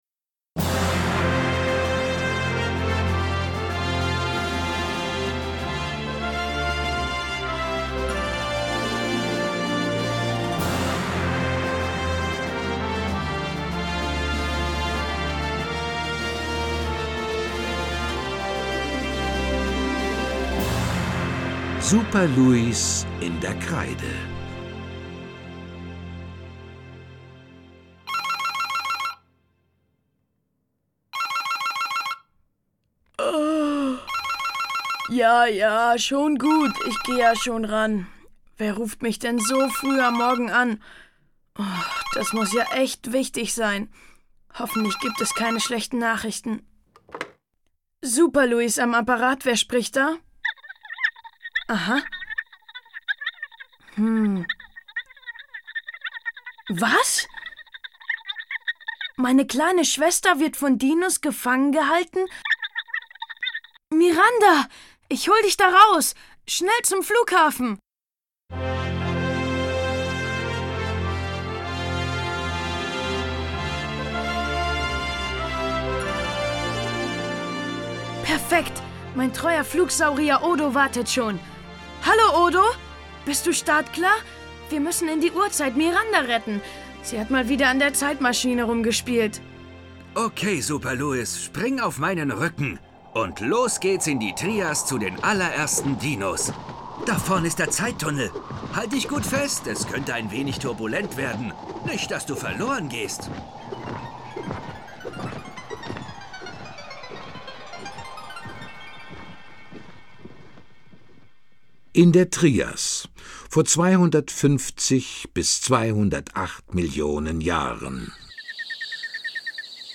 Schlagworte Archäologie • Archäologie; Kindersachbuch/Jugendsachbuch • Dinosaurier • Dinosaurier; Kindersachbuch/Jugendsachbuch • Geschichte • Hörbuch; Lesung für Kinder/Jugendliche • Wissen